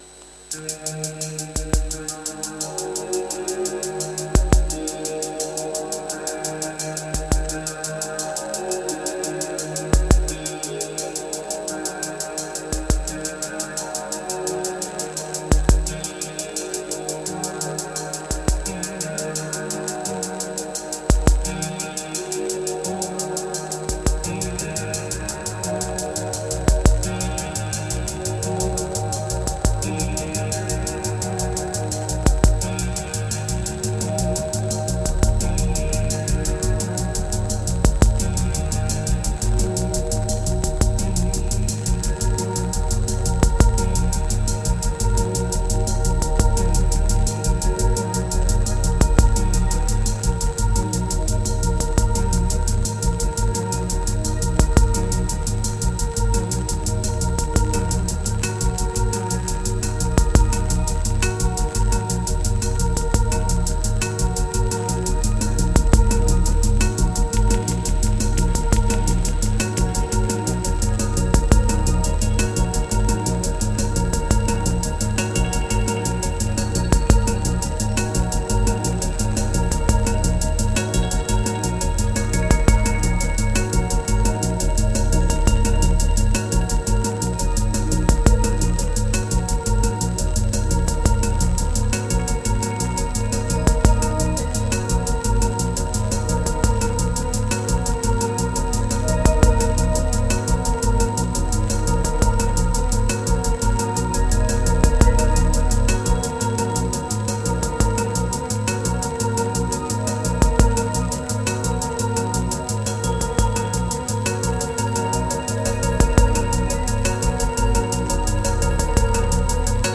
1665📈 - 96%🤔 - 86BPM🔊 - 2022-09-25📅 - 1601🌟